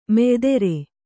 ♪ meydere